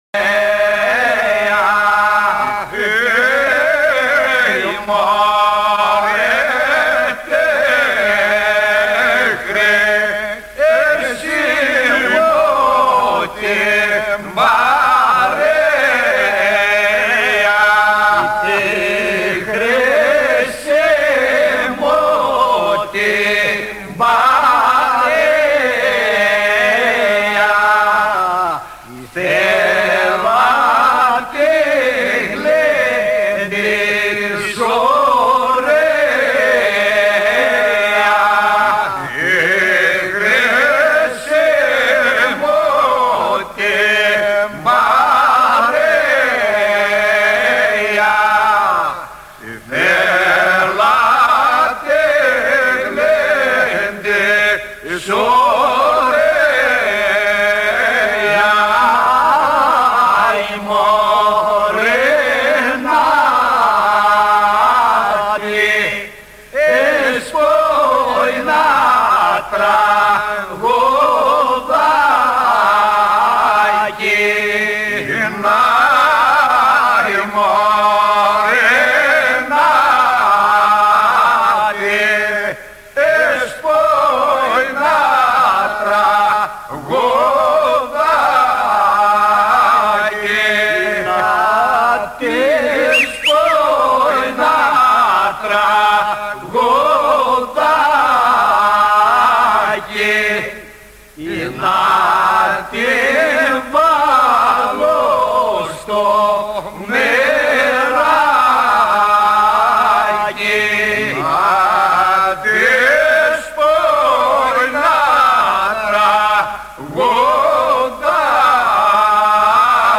Τραγούδι του ανταμώματος και του γλεντιού.